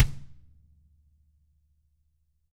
Index of /90_sSampleCDs/ILIO - Double Platinum Drums 2/Partition A/DW KICK D
DW HARD FD-L.wav